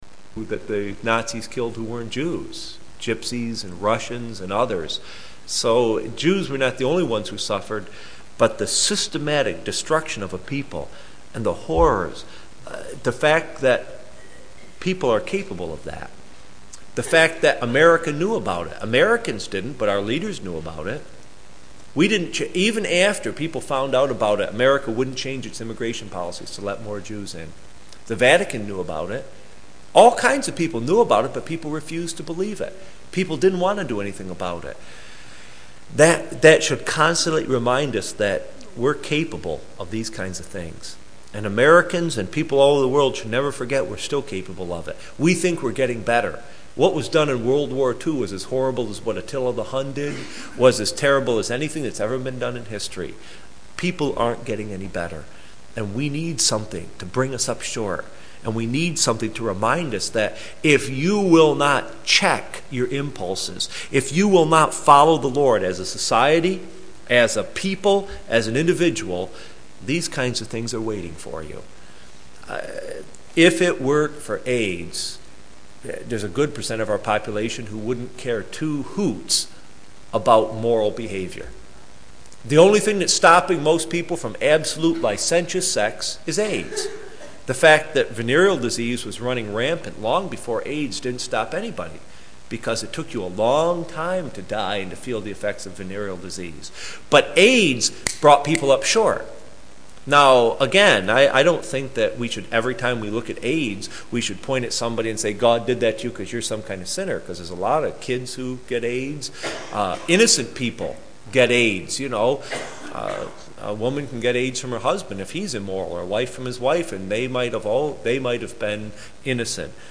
Judges 2:20-3:4 – Sermons